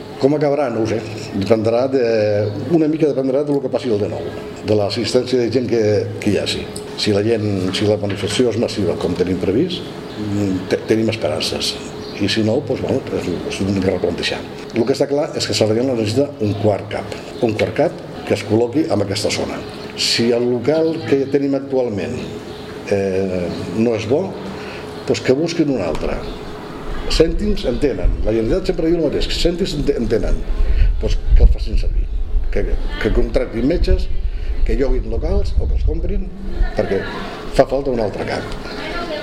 Declaracions: